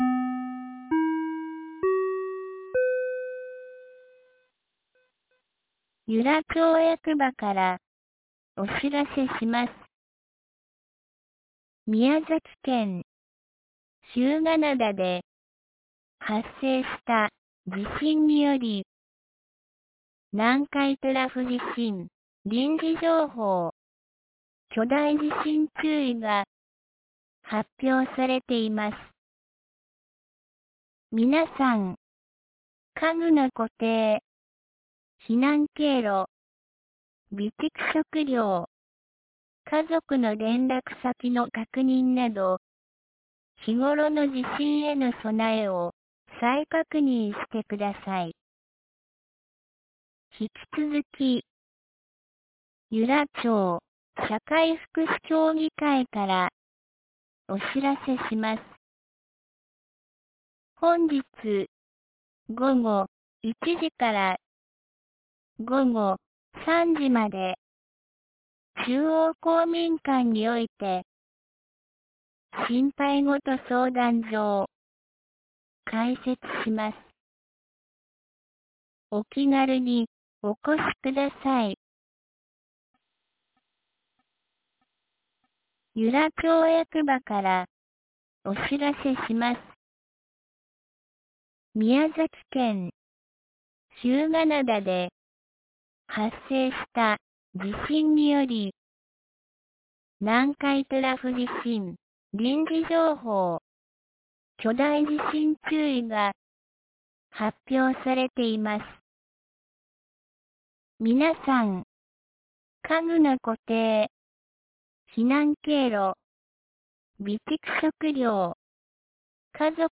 2024年08月13日 12時23分に、由良町から全地区へ放送がありました。